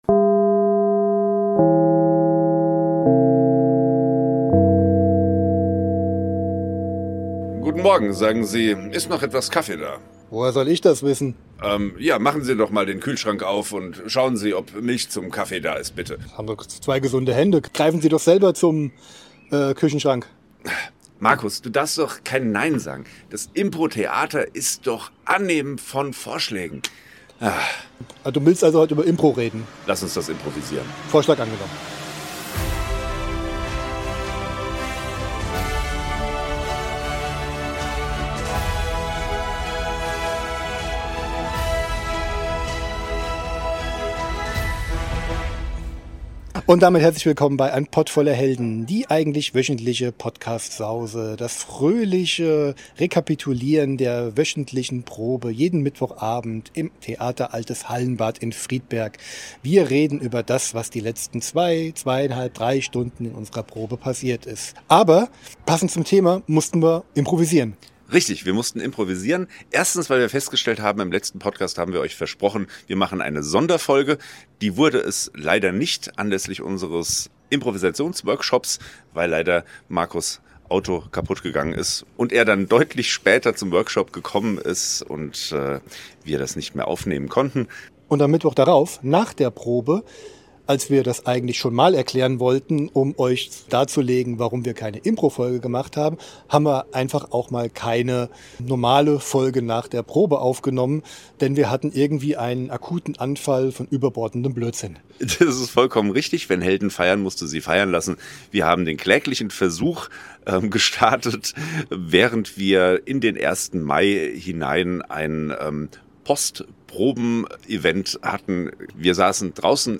Da die reguläre Folge, die unmittelbar nach der Mittwochsprobe aufgenommen wurde nicht so ganz unseren Erwartungen entsprach, wurde auf die Schnelle etwas improvisiert. Da dachten wir, das machen wir gleich mal zum Thema unserer neuen Aufnahme!